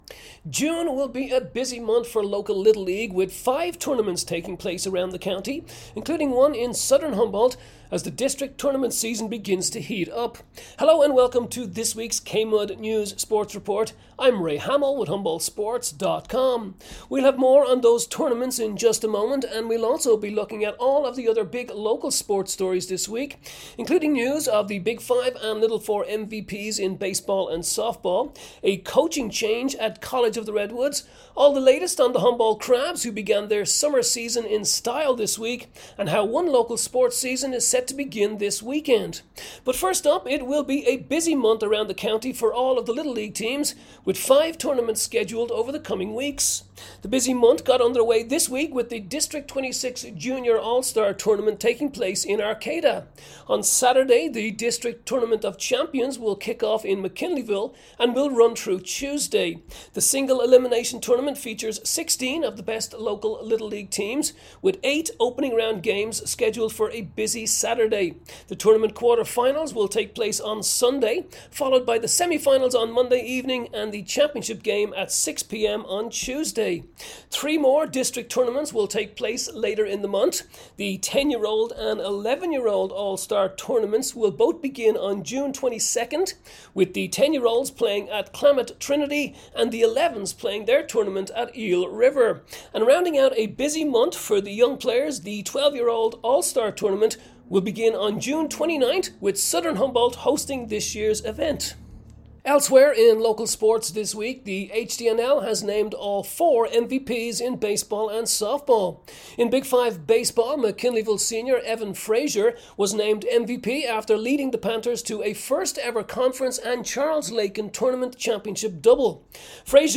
June 6 Kmud Sports Report